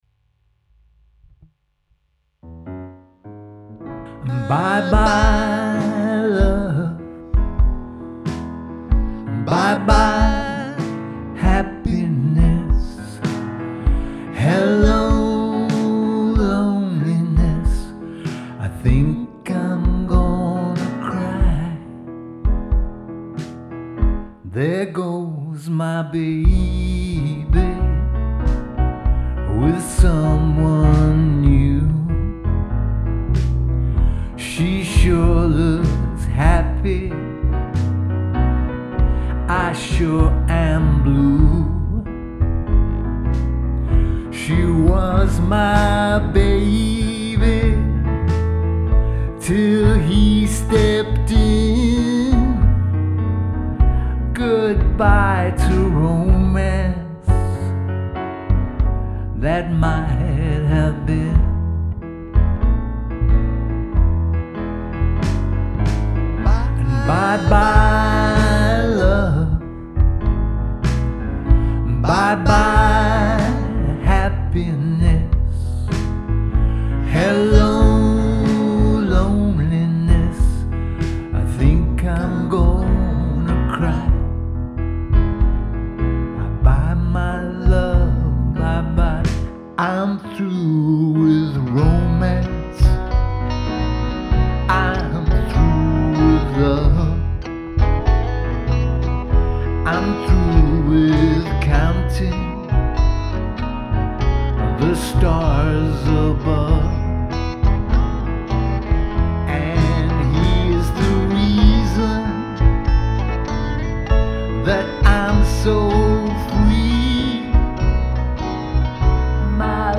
you’ve got the sadness there. Nice country guitar going on.